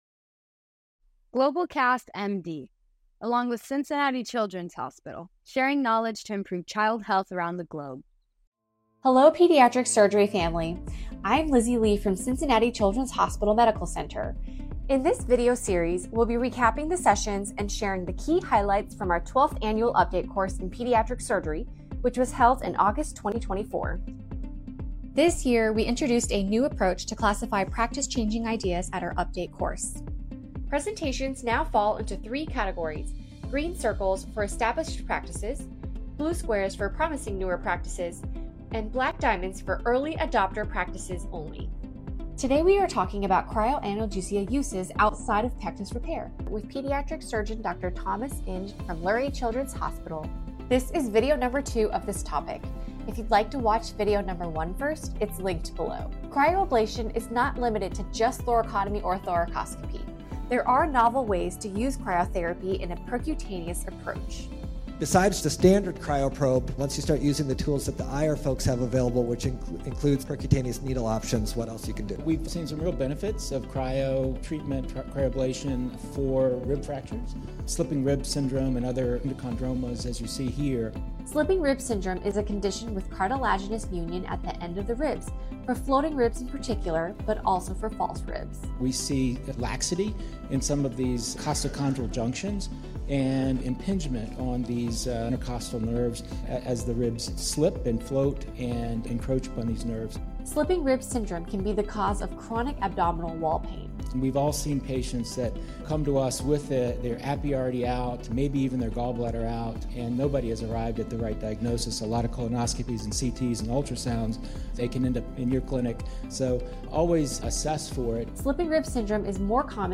In this insightful recap from the 12th Annual Update Course in Pediatric Surgery